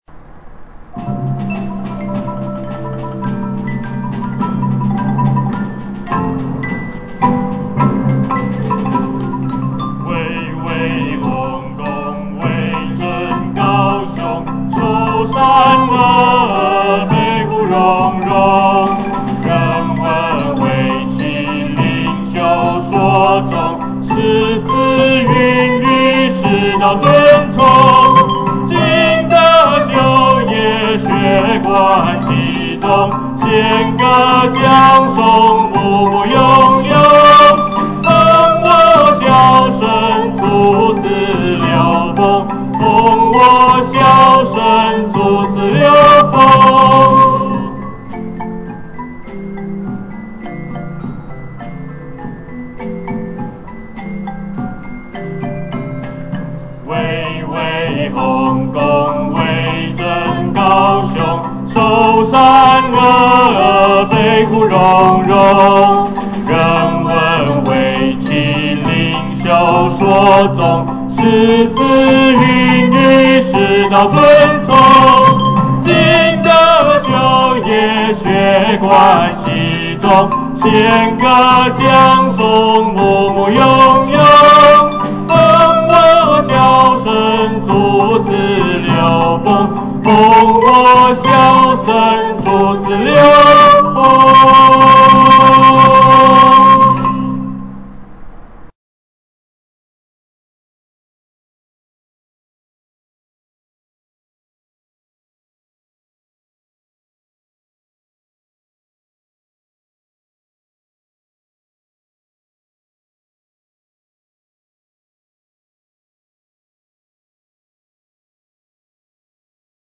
校歌(高師大交響樂團) MP3檔案下載 (另開新視窗)